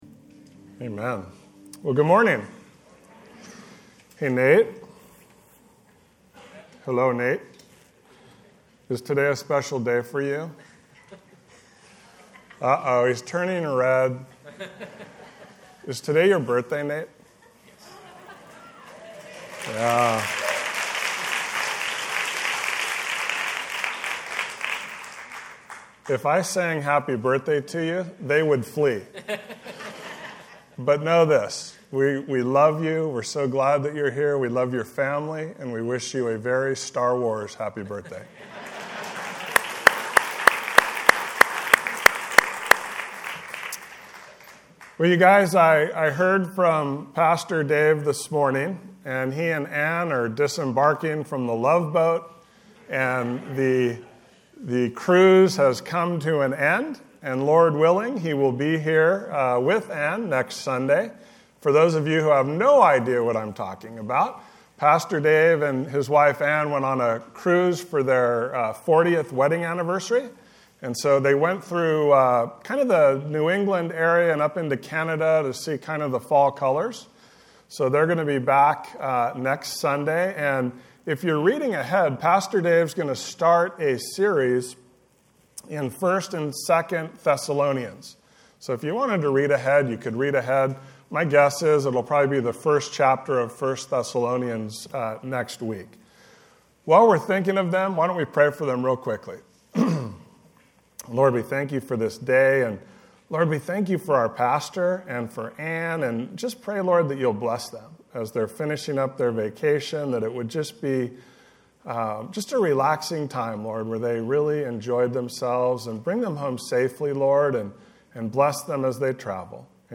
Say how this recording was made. John 8:1-11 A Gracious Savior (Special Service